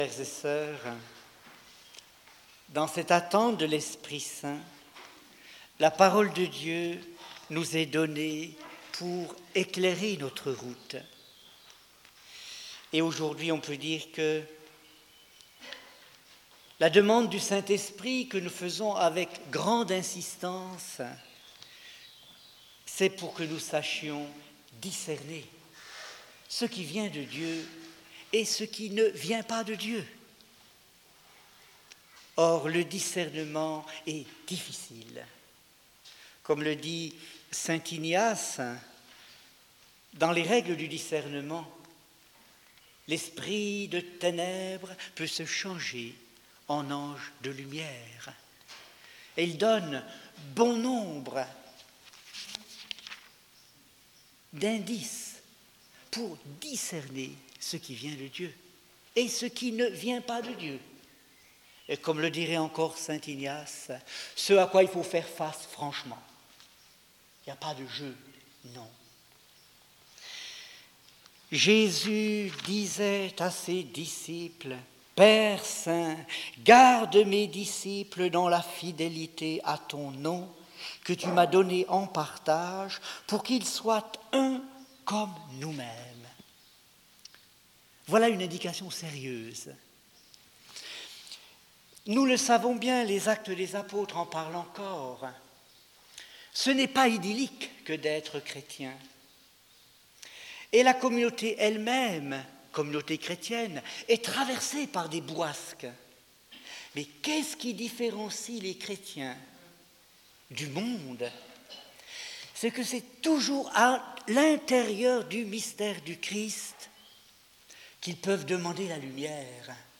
(Homélies de l’Ascension à la Pentecôte, Homélie 8)
discernement_dans_le_christ_homelie_8_.mp3